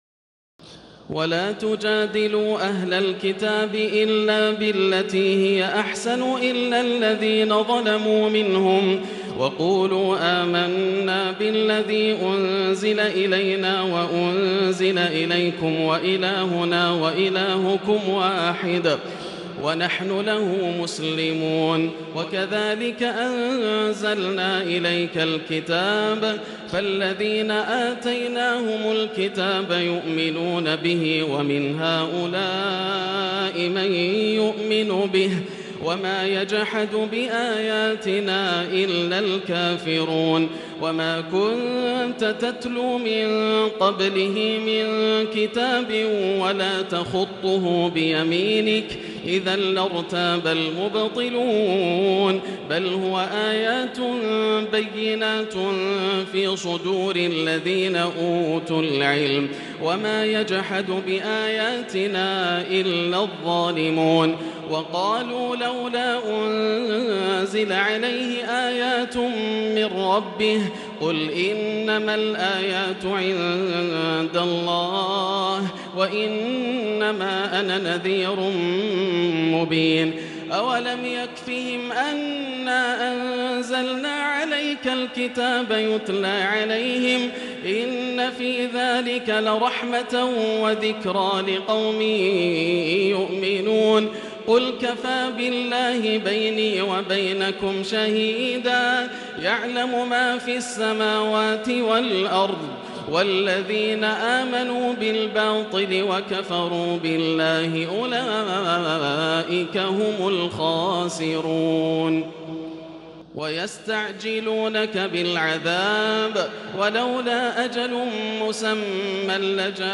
تراويح الليلة العشرون رمضان 1440هـ من سور العنكبوت (46-69) و الروم و لقمان (1-19) Taraweeh 20 st night Ramadan 1440H from Surah Al-Ankaboot and Ar-Room and Luqman > تراويح الحرم المكي عام 1440 🕋 > التراويح - تلاوات الحرمين